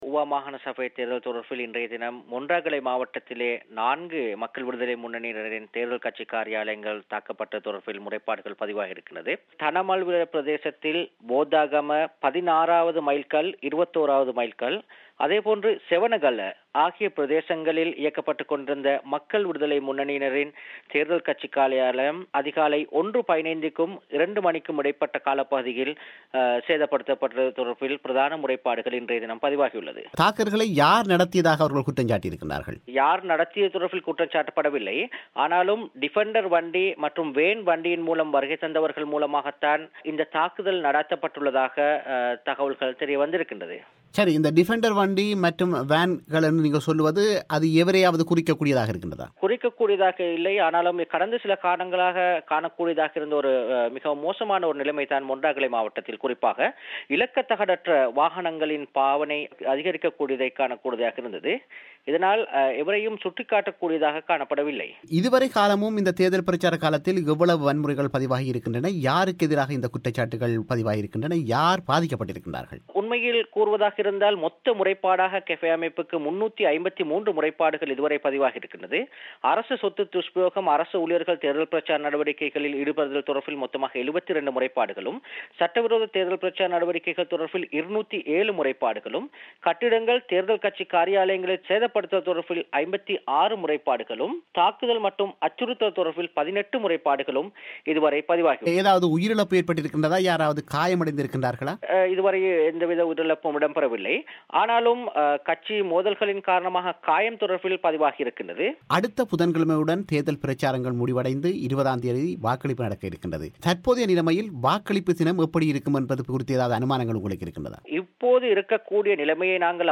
செவ்வியை